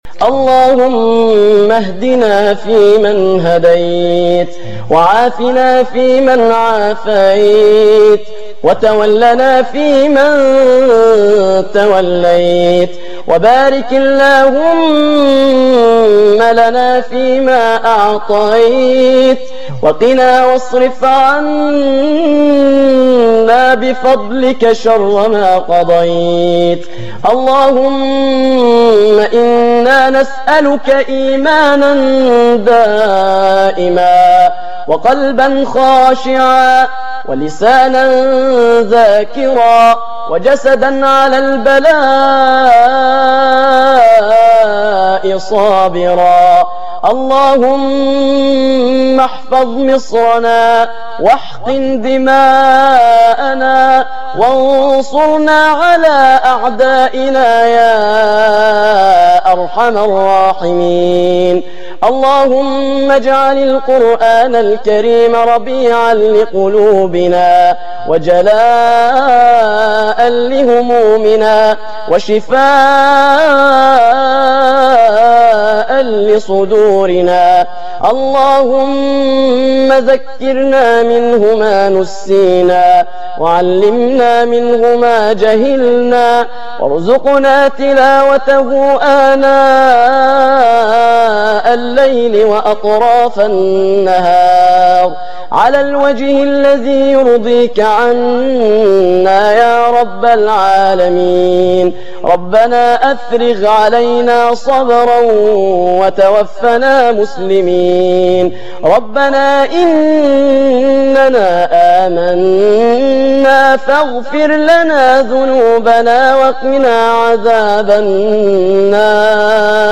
الدعاء
دعاء خاشع ومؤثر
تسجيل لدعاء خاشع ومميز